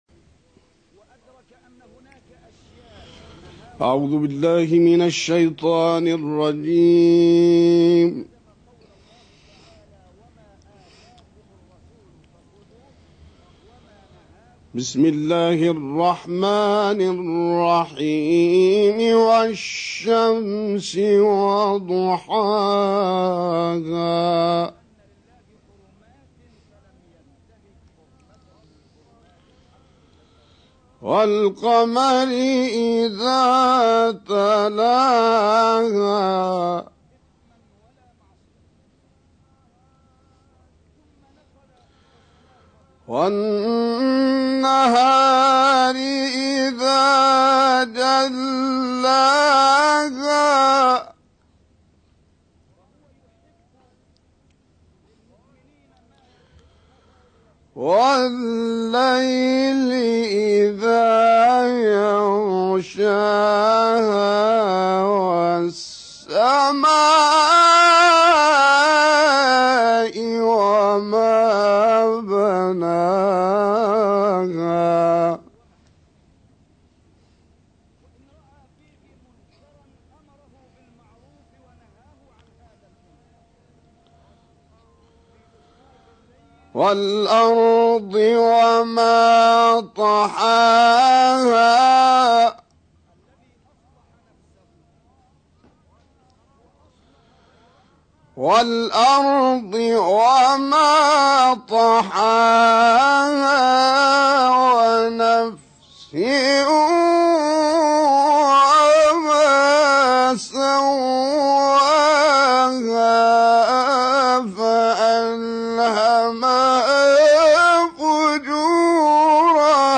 تلاوت سوره شمس